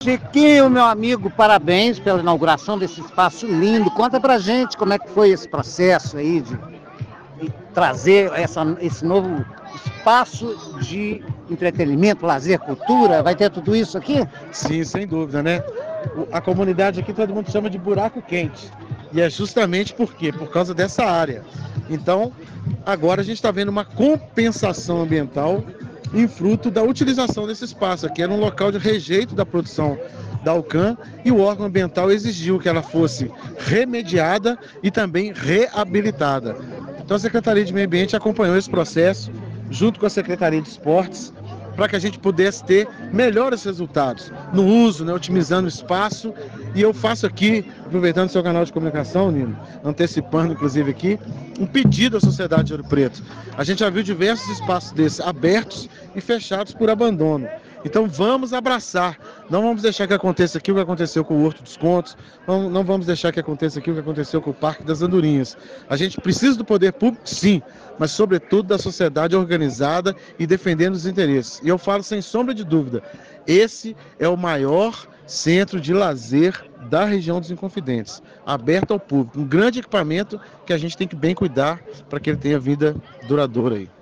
ENTREVISTA SECRETÁRIO DE MEIO AMBIENTE – Chiquinho de Assis faz um apelo às autoridades e à comunidade para que todos cuidem da preservação do novo Parque, para que não ocorra ali o que aconteceu com outros espaços públicos similares que vivenciaram situações de descaso e abandono.